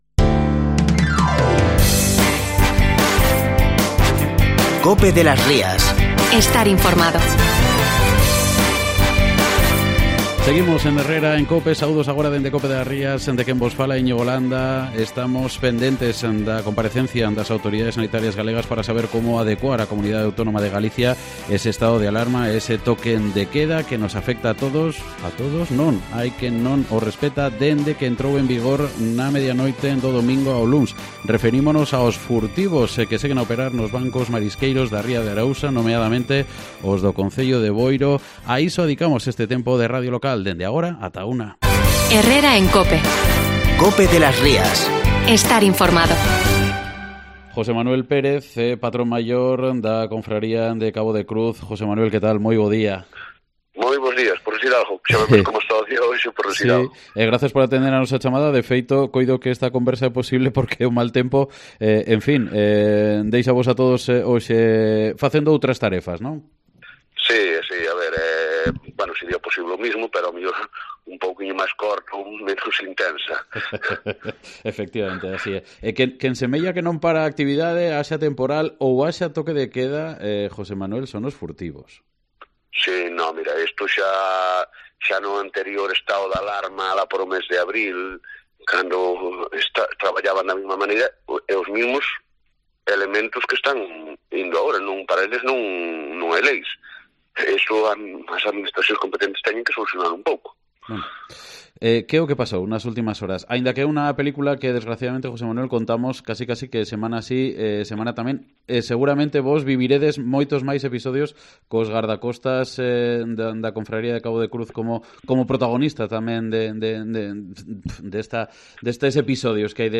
en Herrera en COPE de las Rías